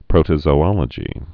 (prōtə-zō-ŏlə-jē)